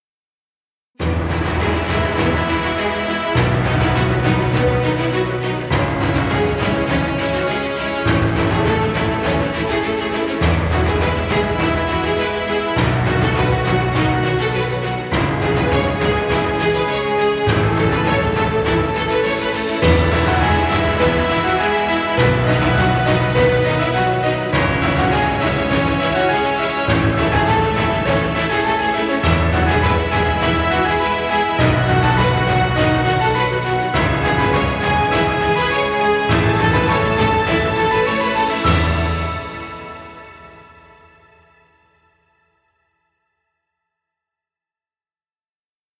Epic inspirational music.